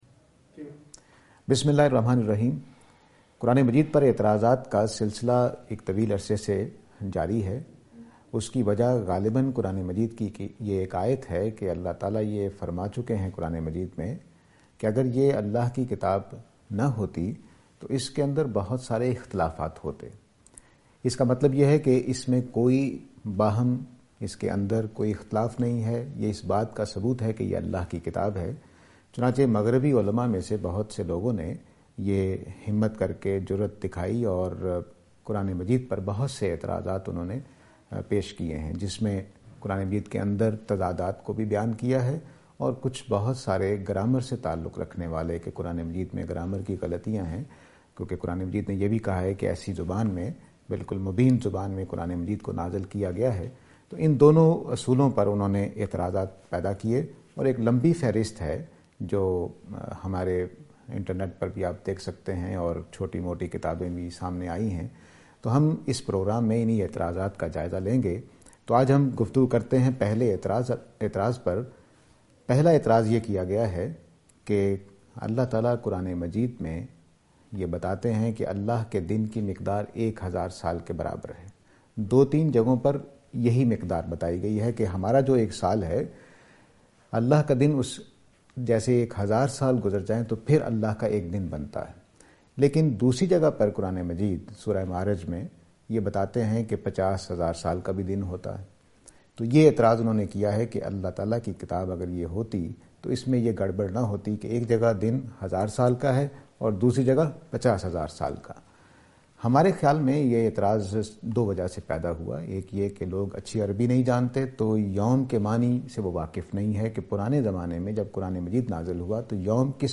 This lecture will present and answer to the allegation "Length of Allah’s day".